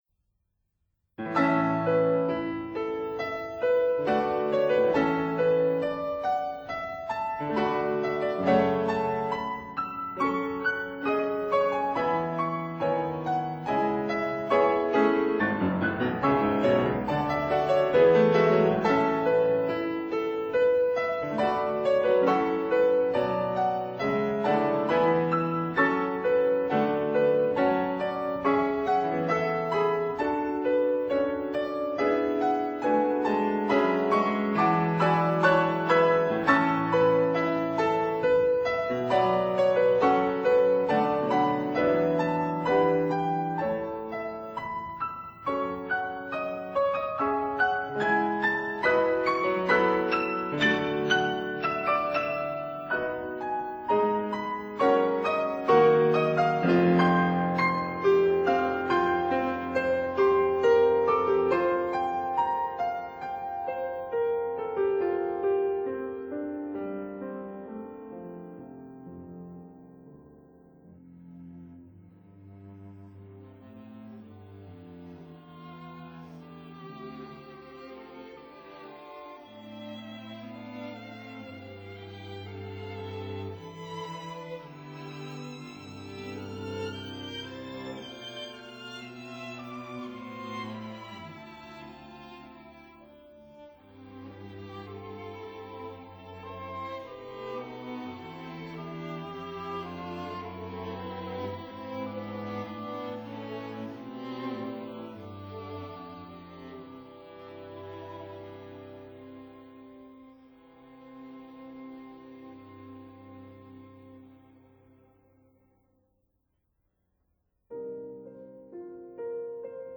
piano
violin
viola
cello